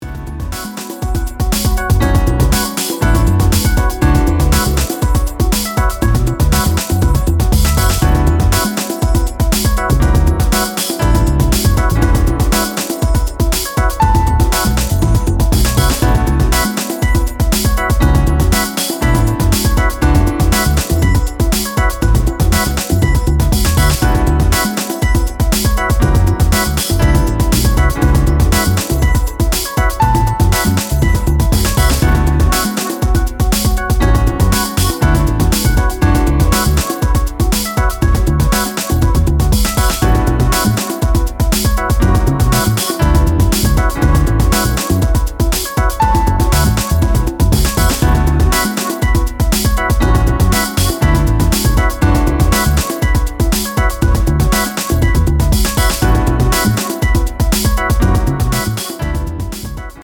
生演奏をたっぷりと用いながら豊かな音楽性を湛えたモダンなジャズ/フュージョン・ハウス〜ブレイクビーツを繰り広げています。